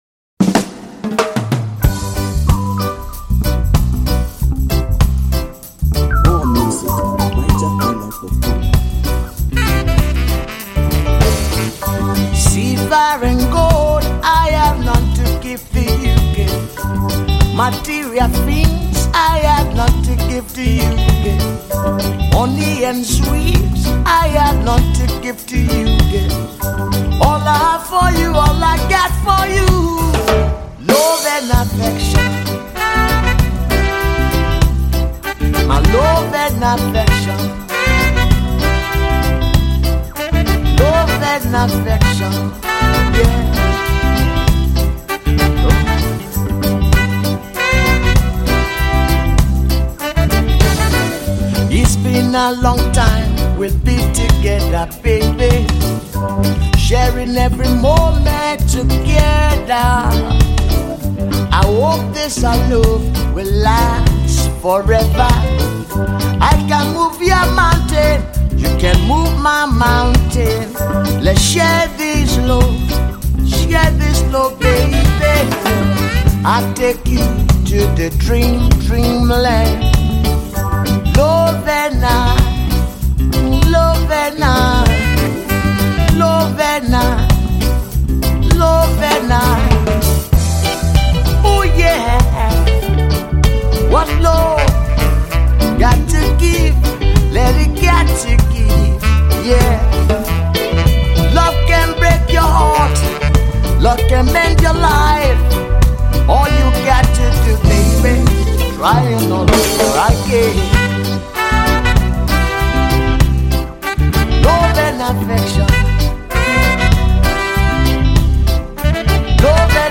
Home » Ragae
Wonderful Reggae Music
highly powerful Reggae Music